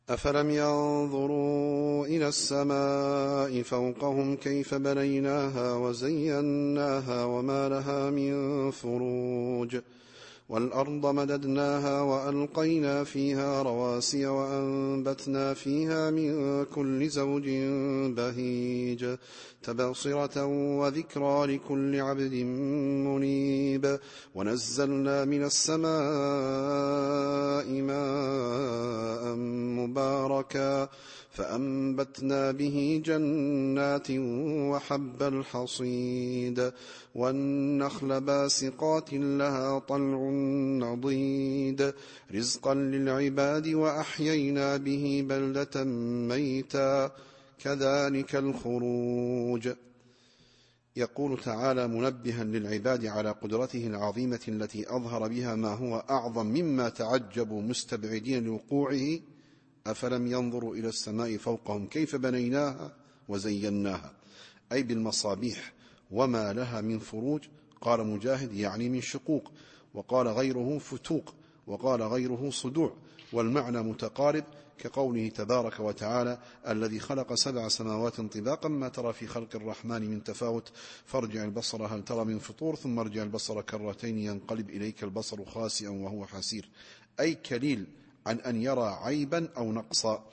التفسير الصوتي [ق / 6]